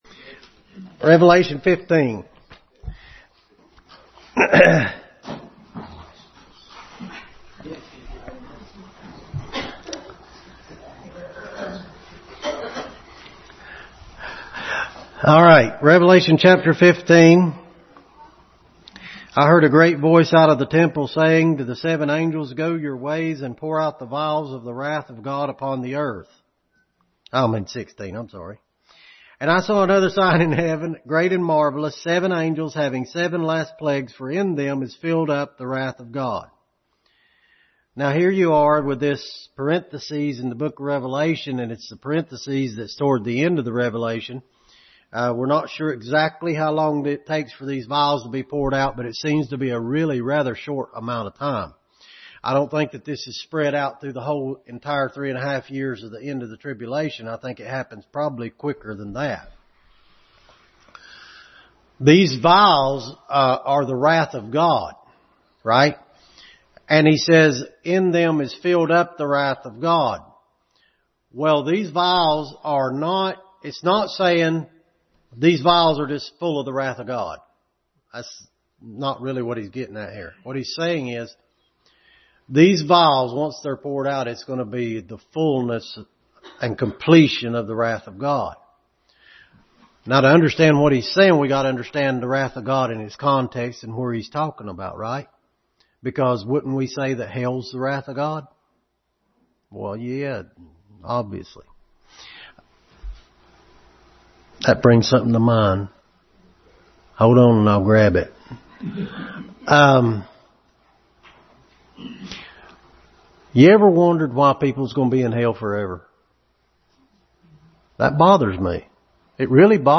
Revelation 15 Passage: Revelation 15, 12:1-6, 1 Timothy 1:17, 6:15 Service Type: Family Bible Hour